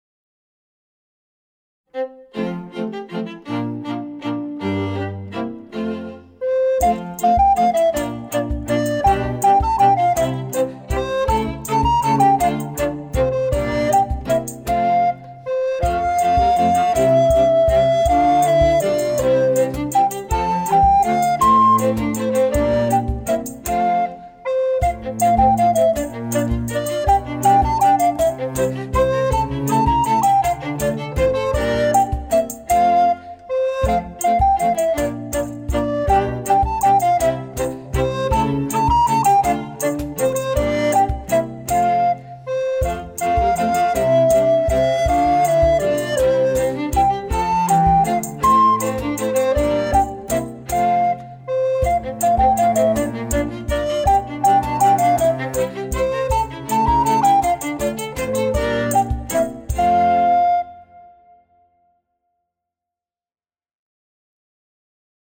Gattung: für Altblockflöte und Klavier
Besetzung: Instrumentalnoten für Blockflöte